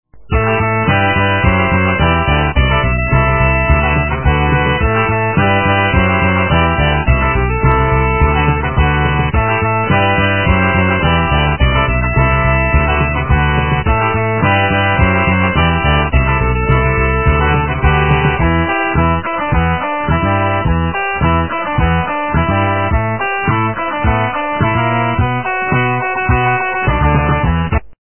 - рок, металл
качество понижено и присутствуют гудки